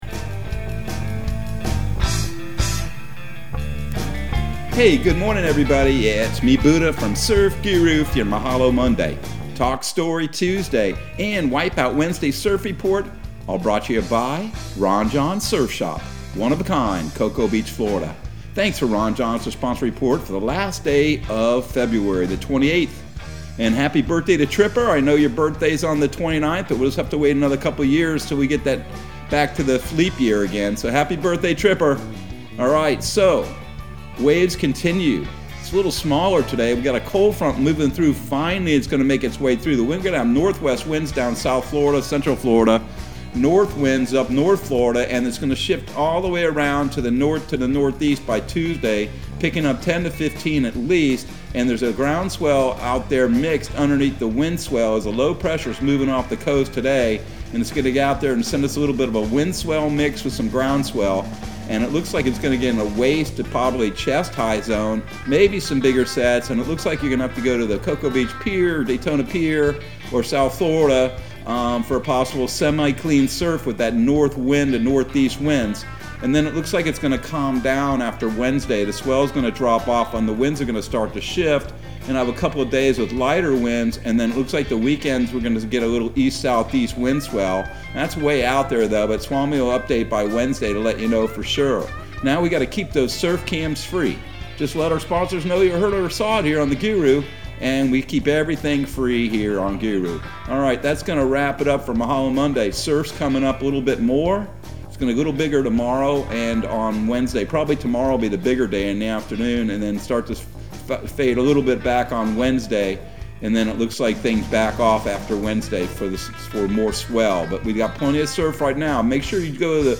Surf Guru Surf Report and Forecast 02/28/2022 Audio surf report and surf forecast on February 28 for Central Florida and the Southeast.